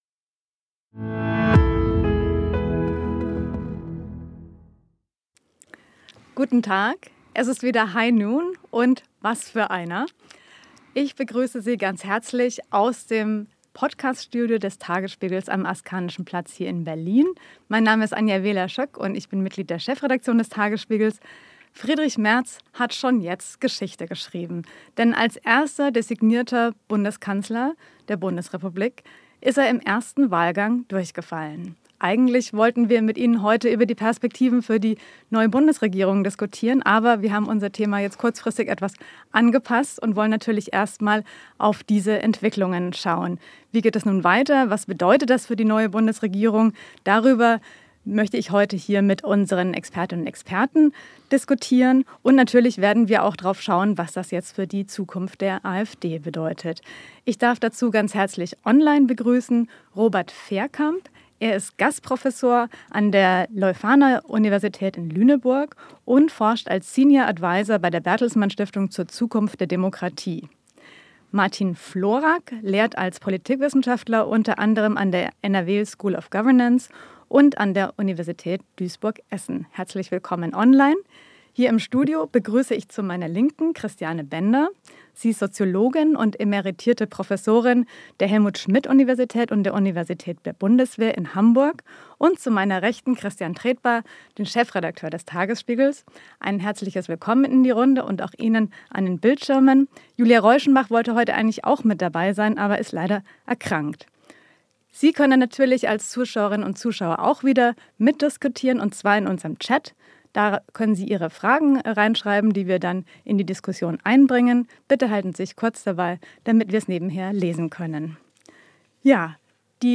Wie stabil wird die schwarz-rote Koalition? Das diskutieren die Experten des Tagesspiegels.